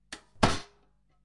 中世纪的战斗 " 盾牌打击4
用Tascam记录金属光栅的声音。
Tag: 中世纪 庆典 打击 战斗 金属 盾击 冲击